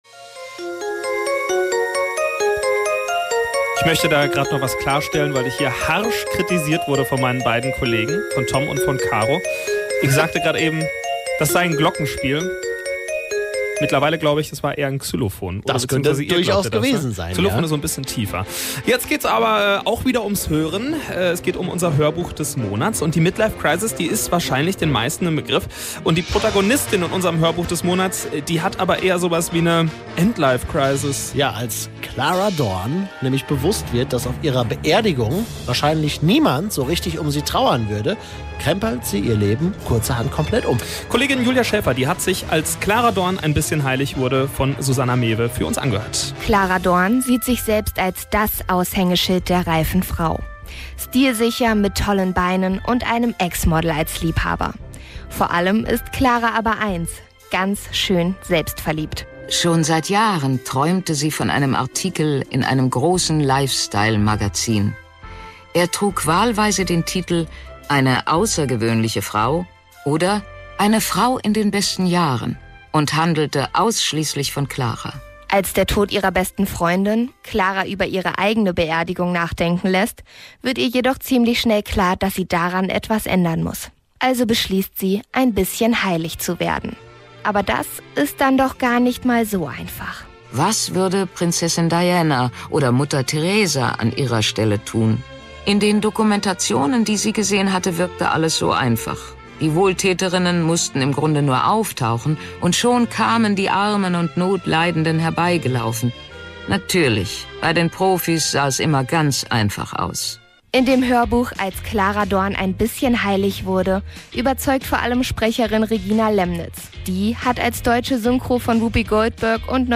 Ein Roman von Susanne Mewe. Sprecherin Regina Lemnitz kennt man vor allem als deutsche Stimme von Whoopi Goldberg und „Roseanne“ sowie als Tierärztin in der TV-Serie „Unser Charly“.